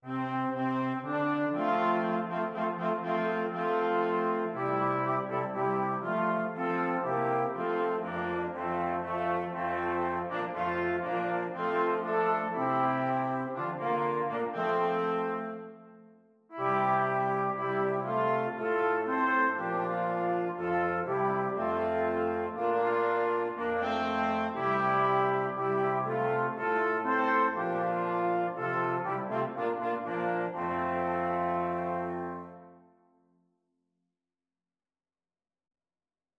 Trumpet 1Trumpet 2French HornTrombone
2/4 (View more 2/4 Music)
Allegro = c.120 (View more music marked Allegro)
Traditional (View more Traditional Brass Quartet Music)